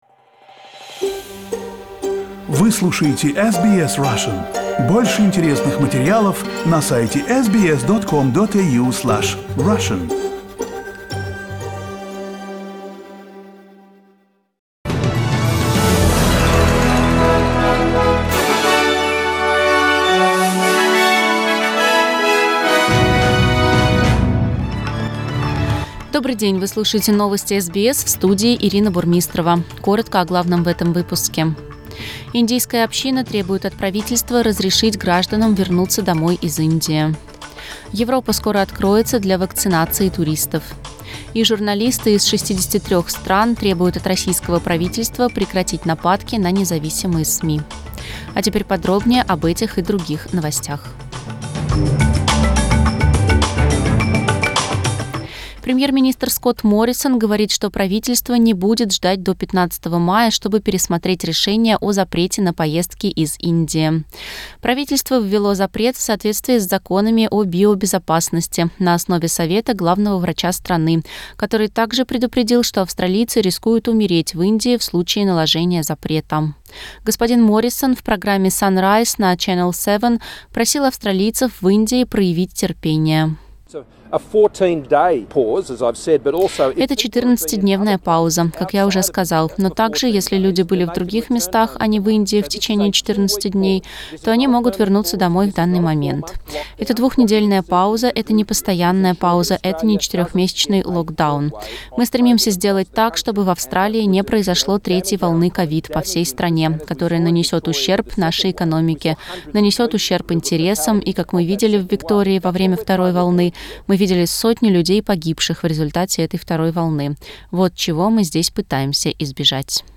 Listen to the latest news headlines in Australia from SBS Russian radio.